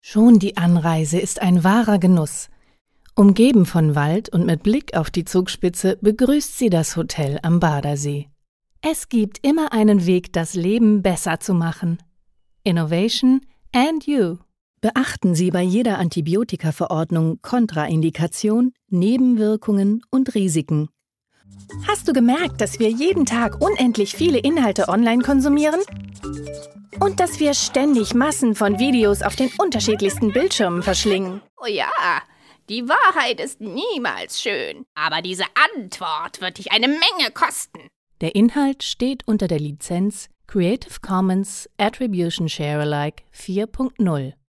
Frische, helle, warme Stimme für E-Learning, Werbung, Guides und Imagefilme.
Sprechprobe: Industrie (Muttersprache):
Fresh, light, warm voice for e-learning, advertorials, guides and corporate films.